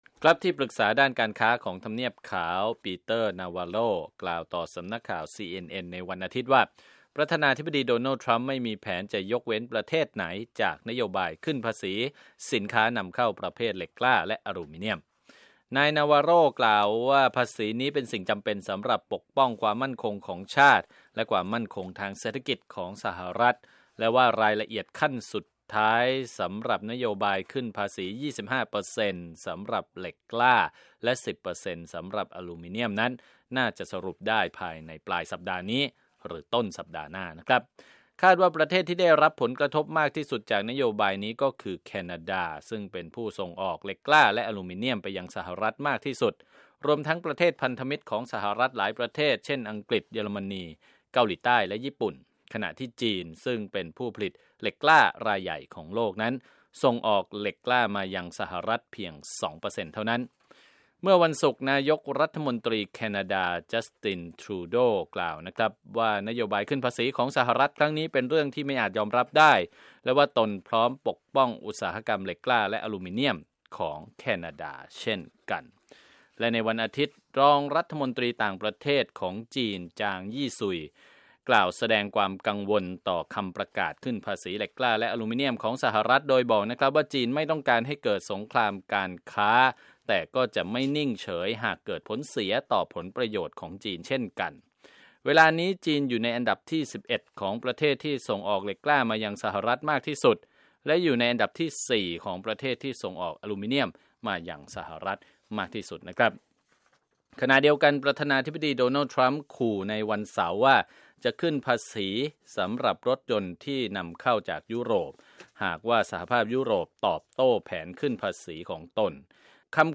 ข่าวธุรกิจ 4 มีนาคม 2561